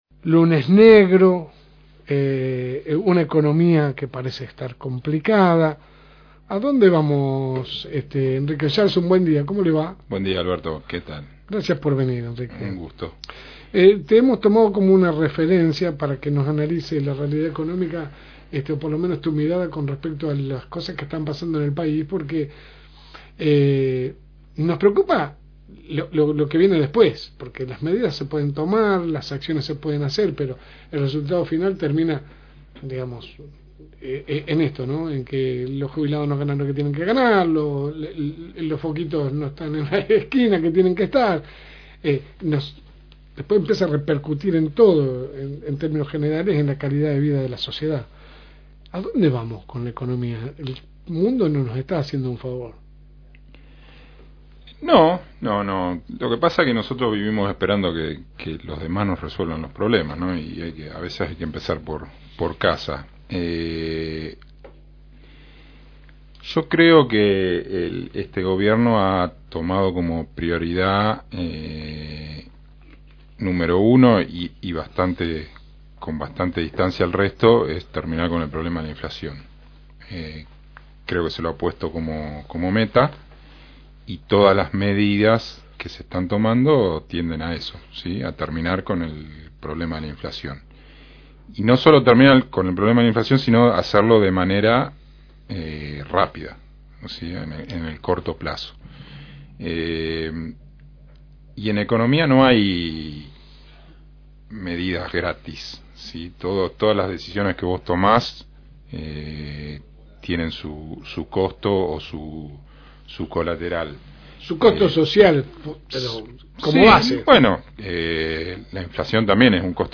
Escucha la nota completa con el contador en el siguiente link.